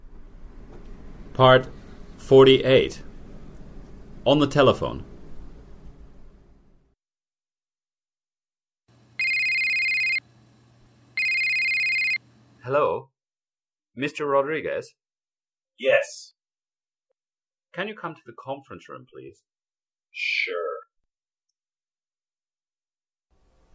A business English dialog series (with questions and answers for beginning level learners)